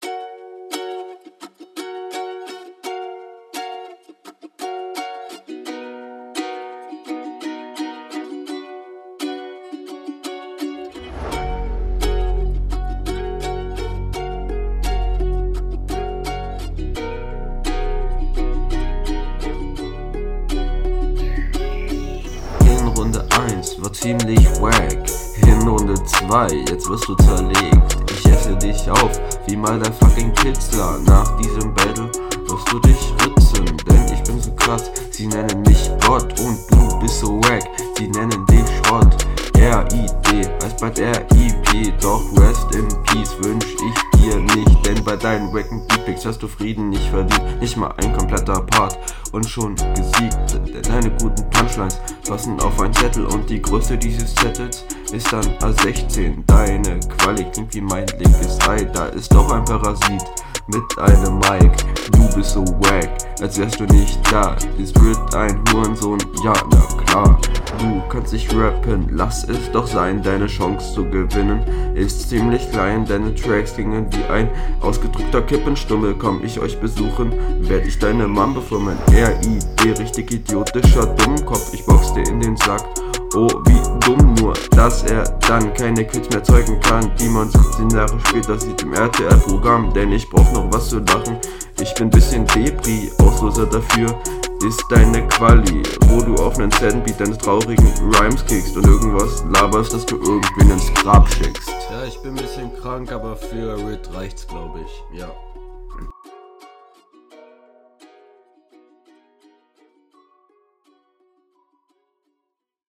Man versteht leider teilweise nicht alles.